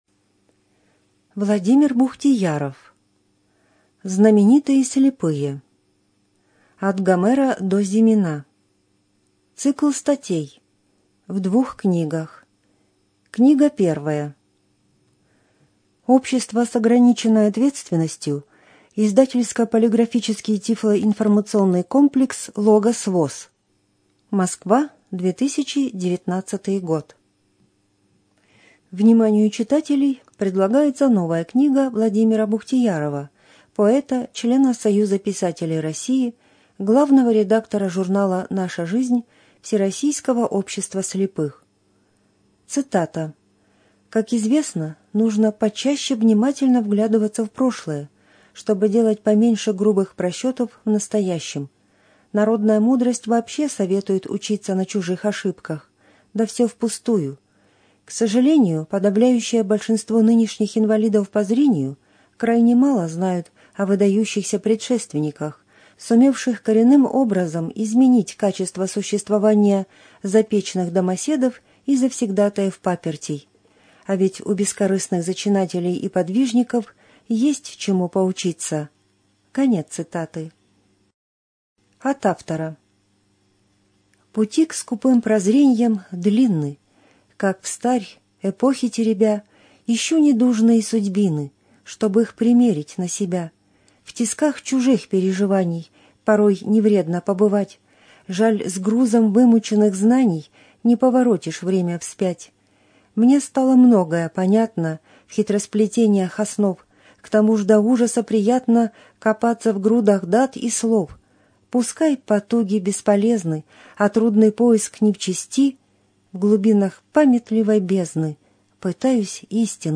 ЖанрБиографии и мемуары
Студия звукозаписиБелгородская областная библиотека для слепых имени Василия Яковлевича Ерошенко